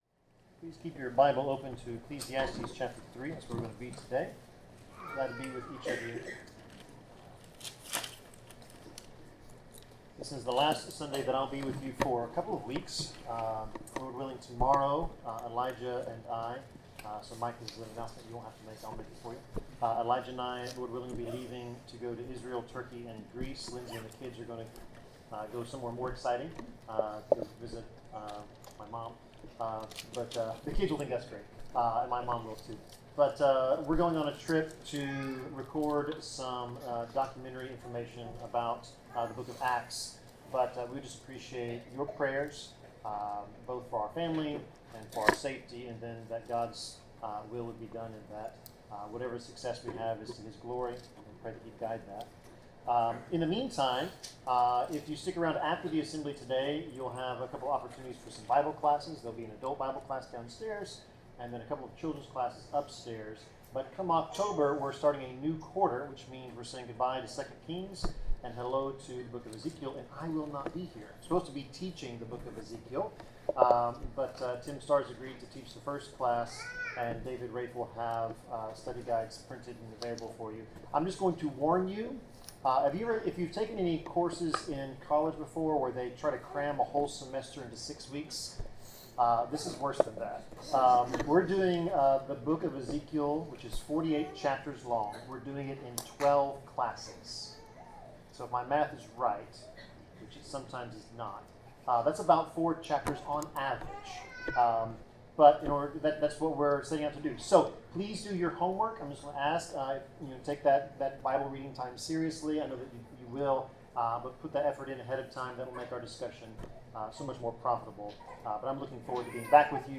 Passage: Ecclesiastes 3:1-3 Service Type: Sermon